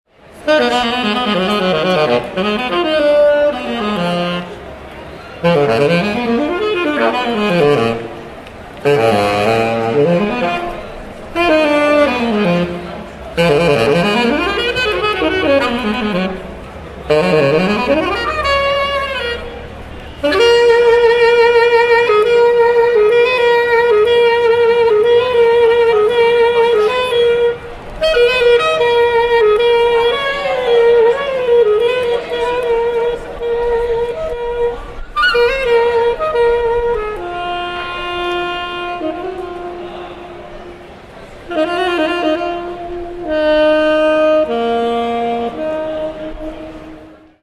saxophone.wav